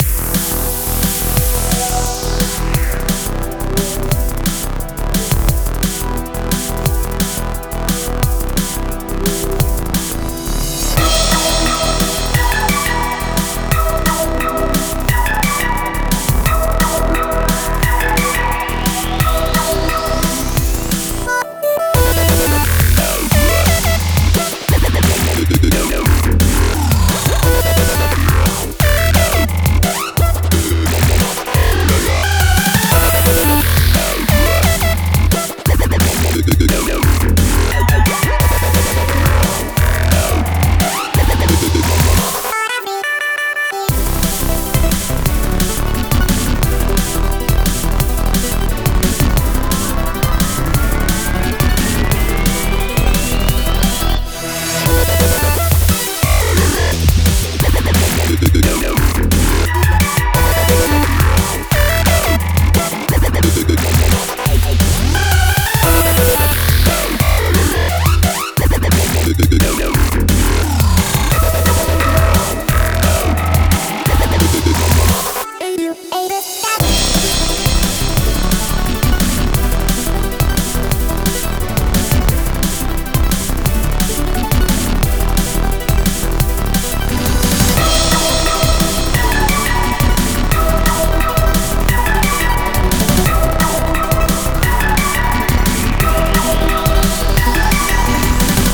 BPM175は速い🏎
◆ジャンル：Drumstep/ドラムステップ
-10LUFSくらいでマスタリングしております。